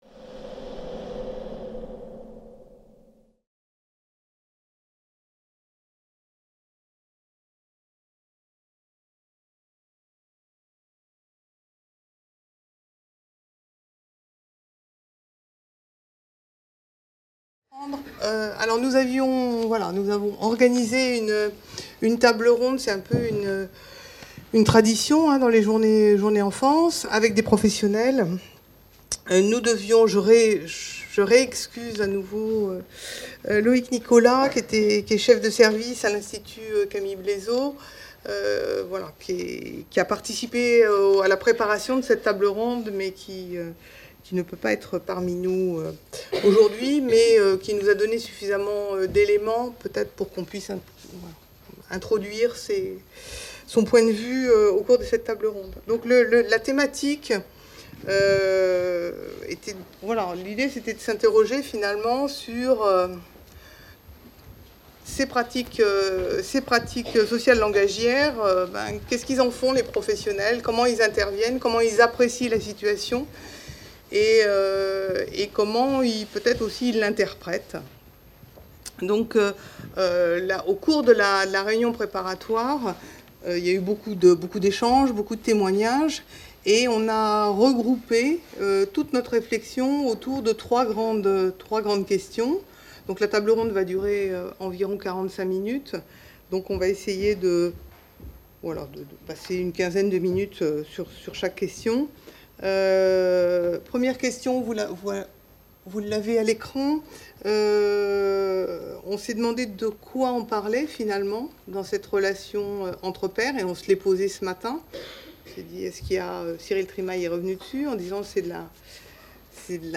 CIRNEF 24 | 03 | Table ronde | Échelle d’appréciation et manière d’agir de professionnels à propos de ces pratiques sociales et langagières | Canal U